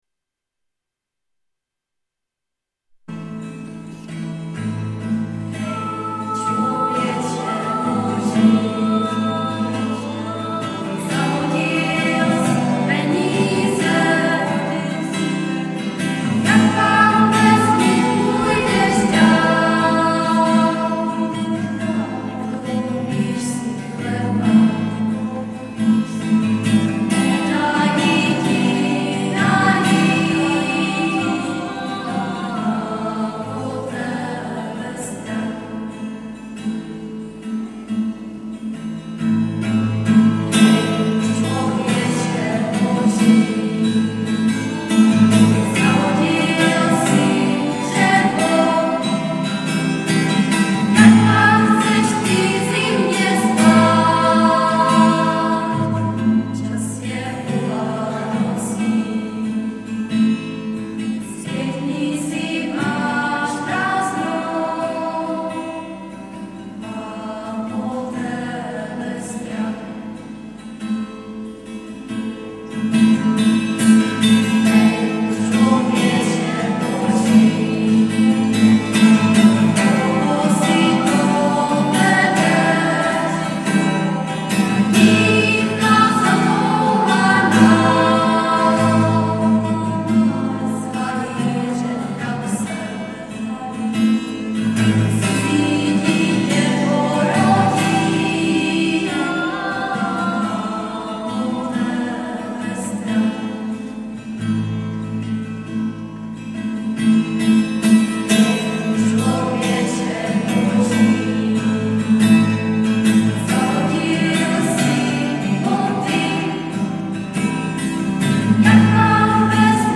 ADVENTNÍ KONCERT
Březová … kostel sv. Cyrila a Metoděje ... neděla 9.12.2007
... pro přehrátí klepni na názvy písniček...bohužel ně skomírala baterka v MD ...